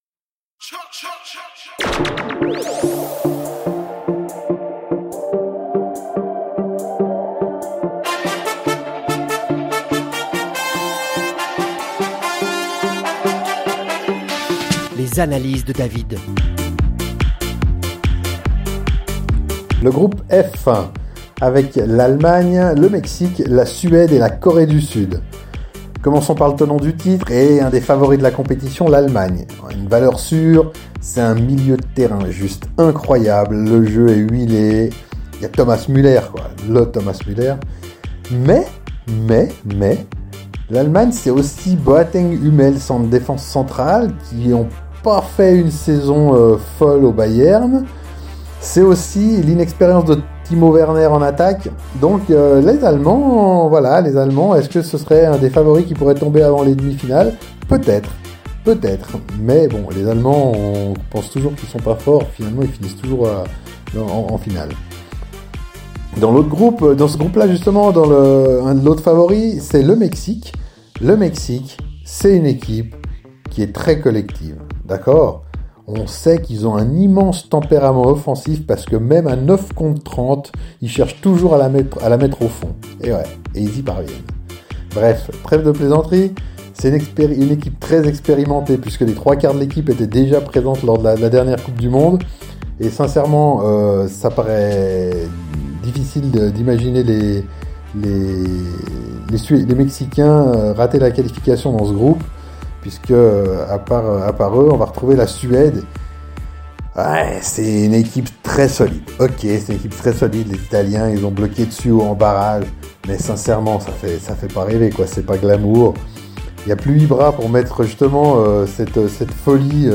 avec sarcasme et polémique.